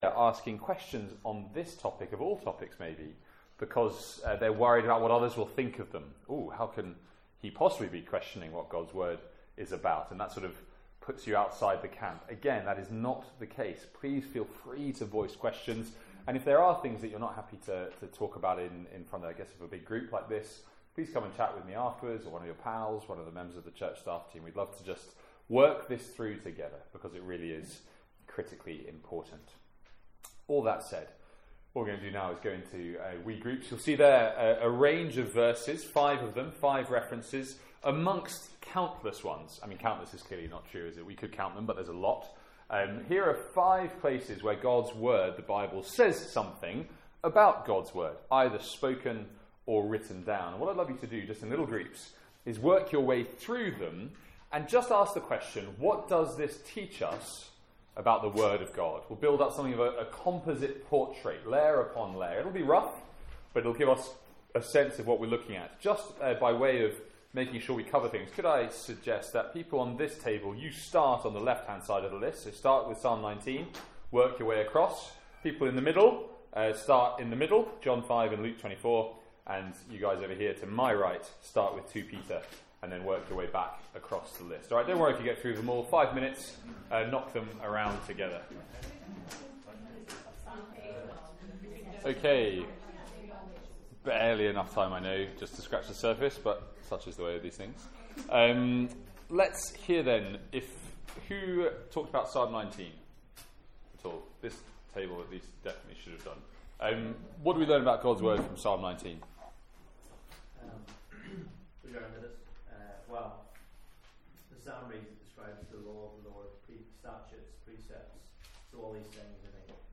From our second student lunch of the academic year.
Note – Group discussion edited out and question time begins at 40min.